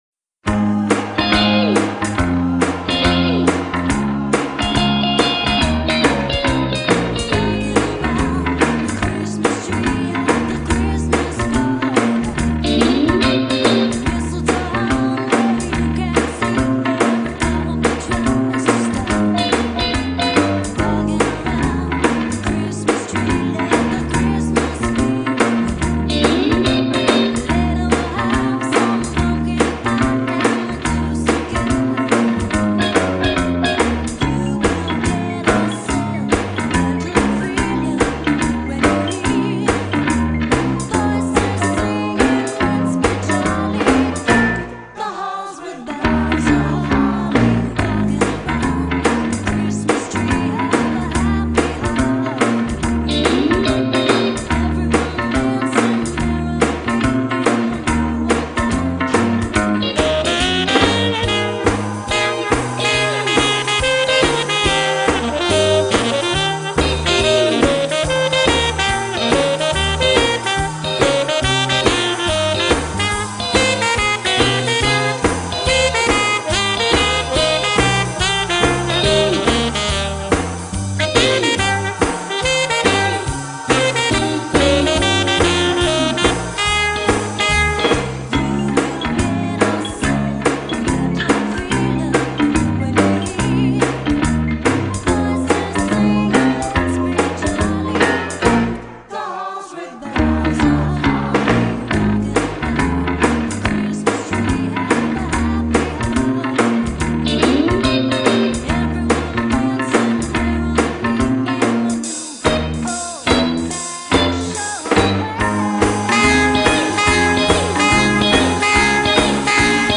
Karaoke